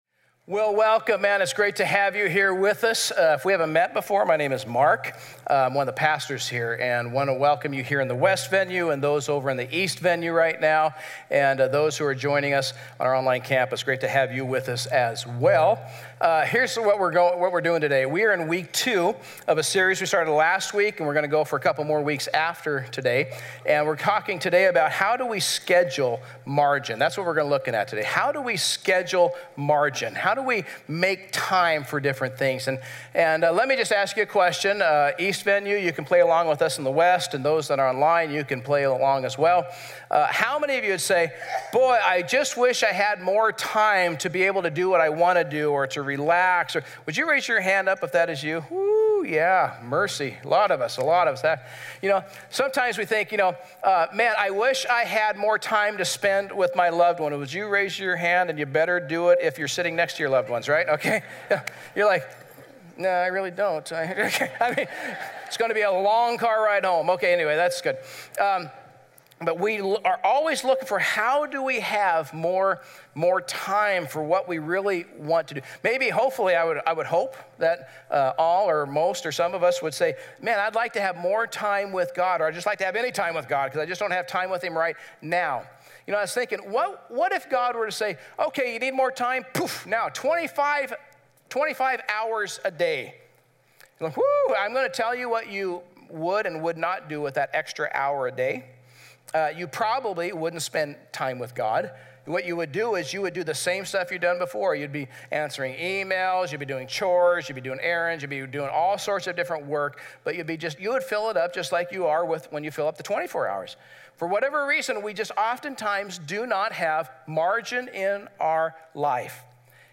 A message from the series "Margin."